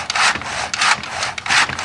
Hand Saw Sound Effect
Download a high-quality hand saw sound effect.
hand-saw.mp3